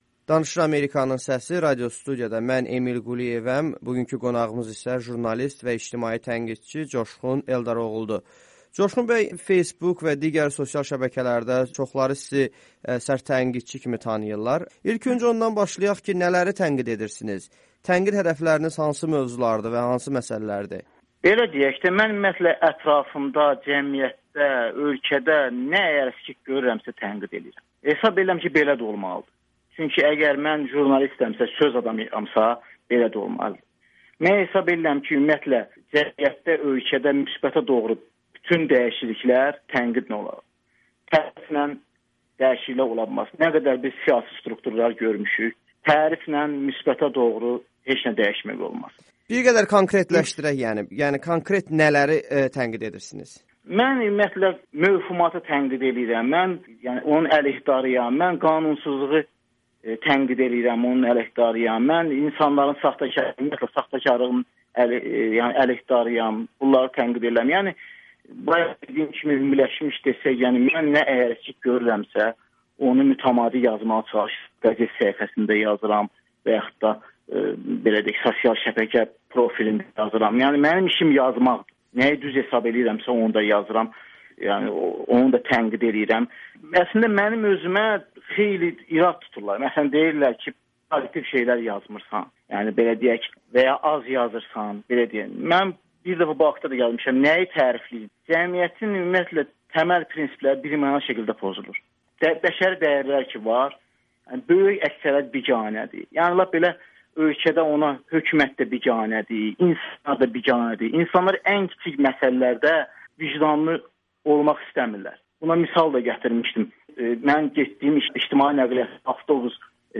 İctimai tənqidçi Amerikanın Səsinə müsahibə verib